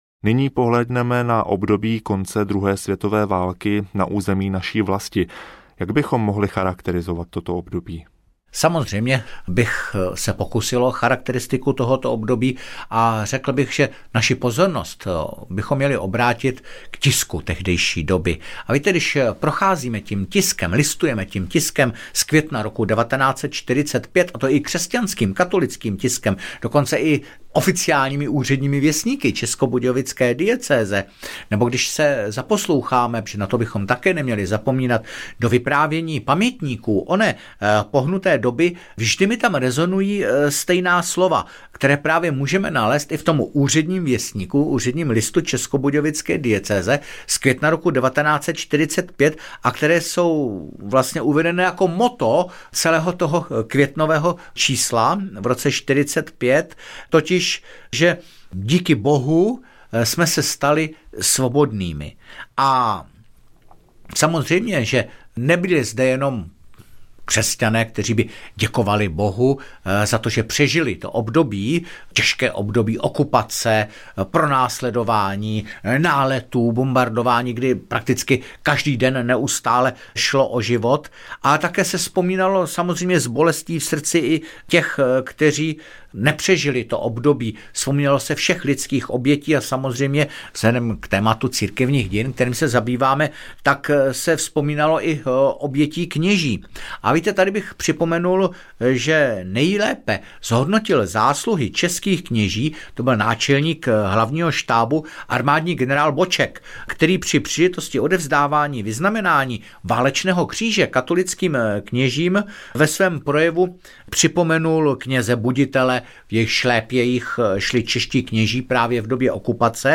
Církevní dějiny v zrcadle času III. audiokniha
Ukázka z knihy